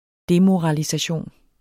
Udtale [ ˈdemoʁɑlisaˌɕoˀn ]